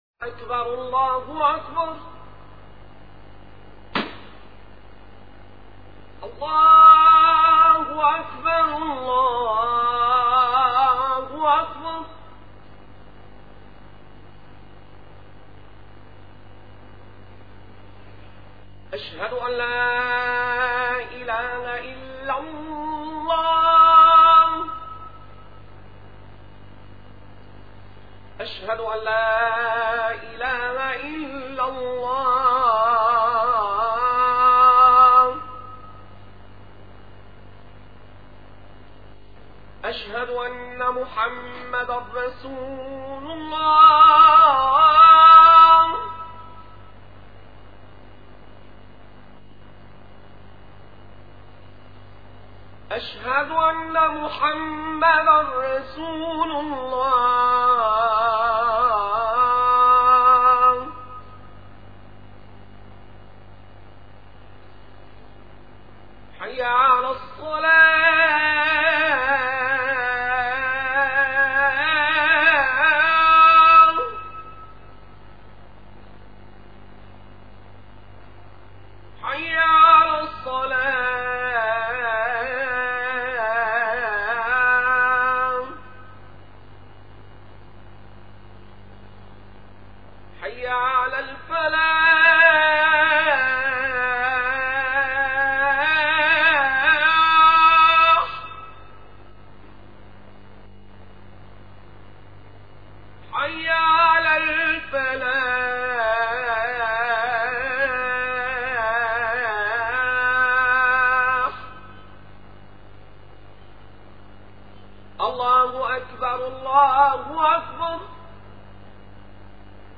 - الخطب - حقيقة الإيمان